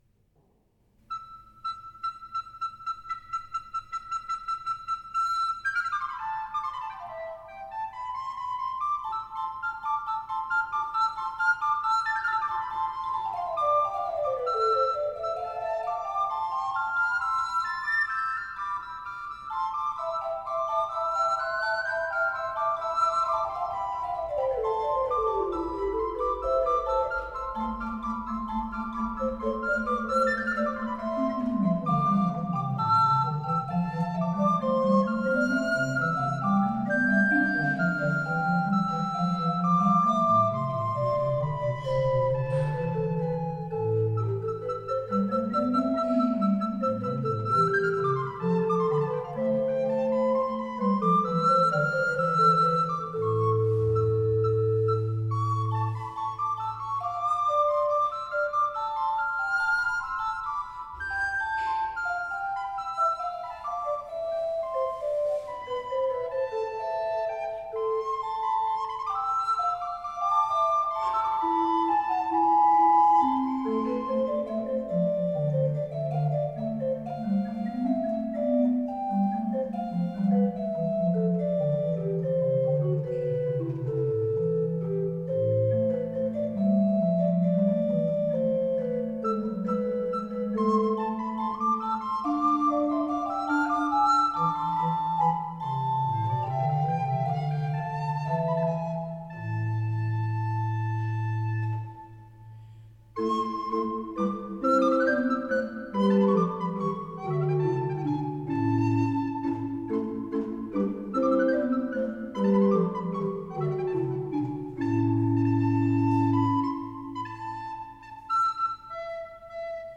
Flötenmusik